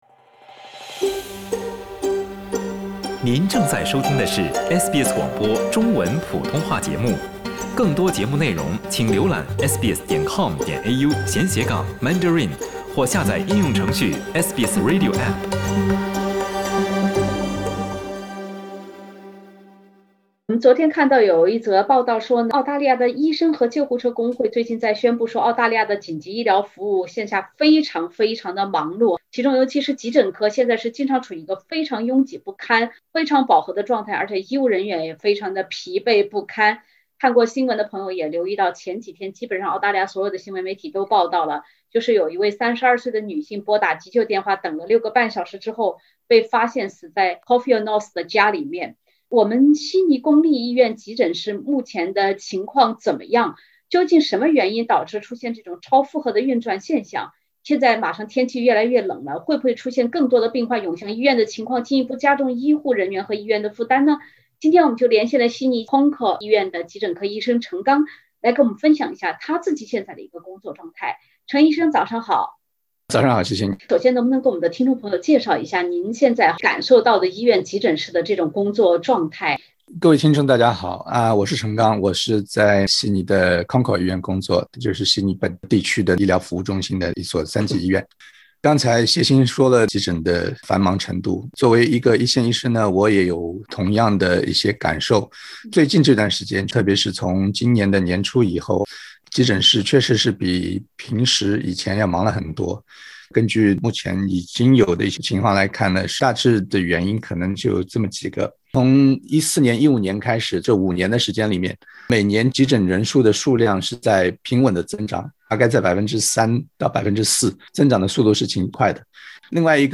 一名32岁的女性在拨打急救电话六个半小时后被发现死在家中，悉尼急诊科医生结合医疗系统目前实际情况整理出满满干货，教大家眼下看病如何才会不耽误。（点击封面图片，收听完整采访）